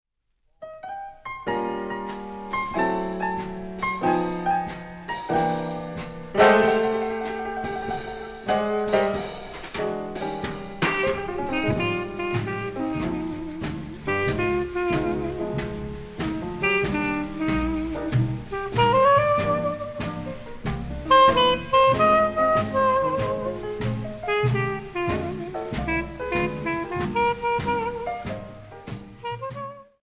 swing, bebop and free jazz
Bebop Easy Listening Instrumental Jazz Swing View All